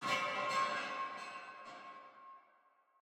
Commotion22.ogg